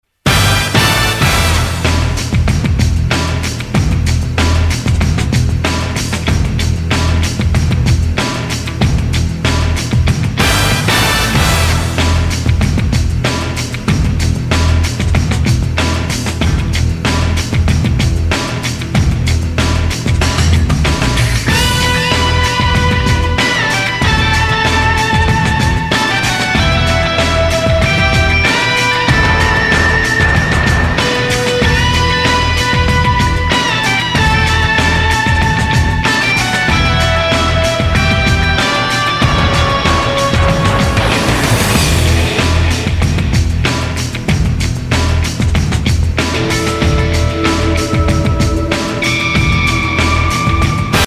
• Качество: 128, Stereo
громкие
эпичные